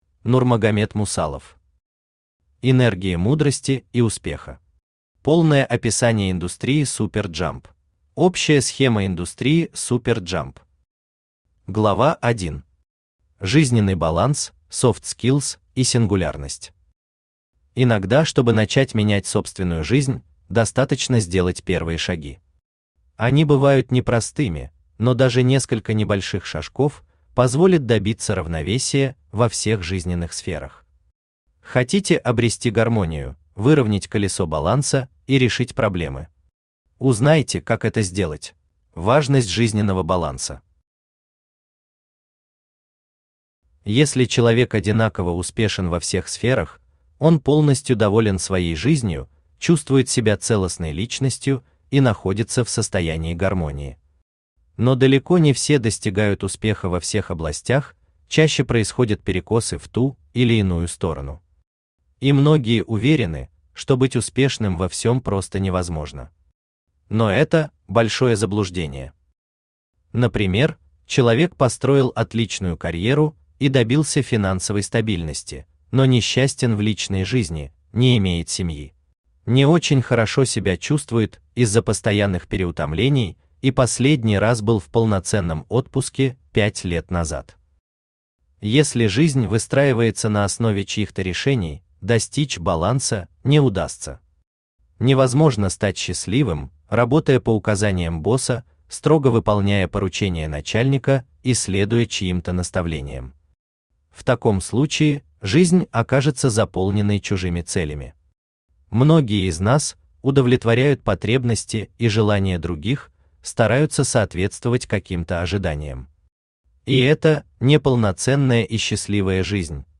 Аудиокнига Энергия Мудрости и Успеха. Полное описание индустрии Super Jump | Библиотека аудиокниг
Читает аудиокнигу Авточтец ЛитРес.